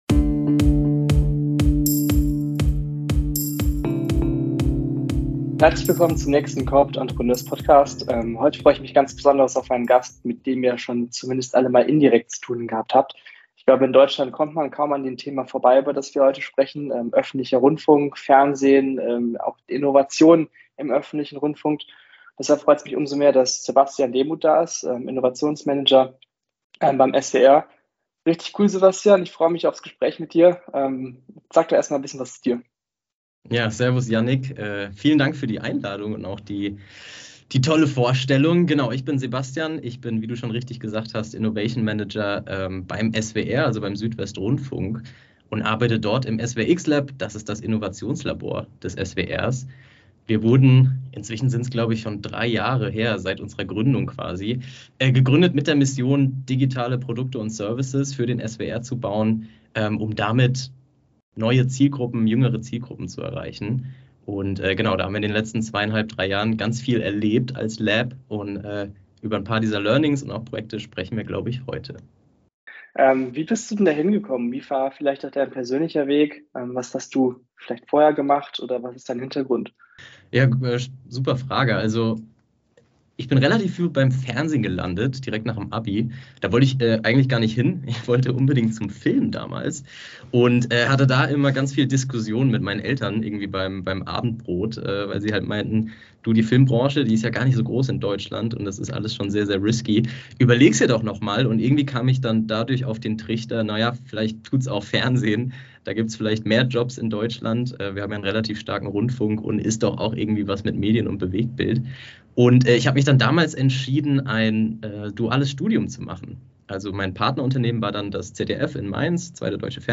Willkommen zur zweiten Interview Folge der neuen Staffel des Corporate Entrepreneur Podcasts!